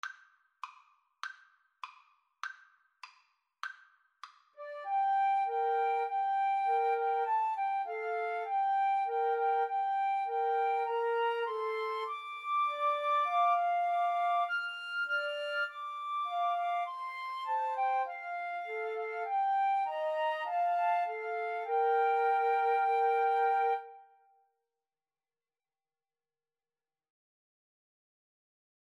Free Sheet music for Flute Trio
Moderato
Bb major (Sounding Pitch) (View more Bb major Music for Flute Trio )